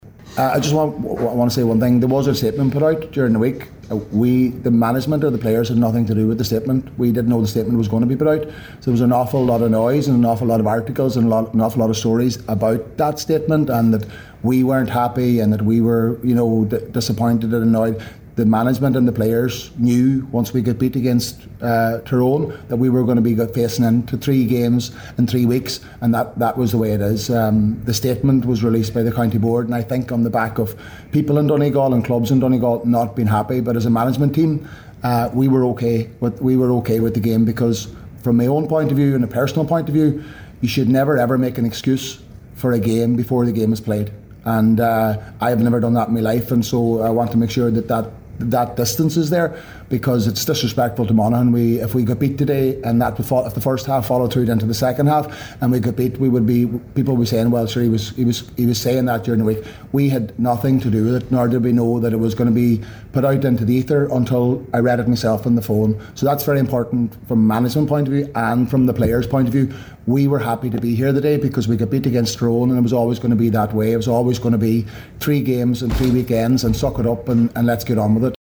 Donegal manager Jim McGuinness
After today’s win, following a media scrum, McGuinness addressed the statement released by Donegal GAA regarding the fixing of the game for Saturday instead of Sunday.